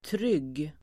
Uttal: [tryg:]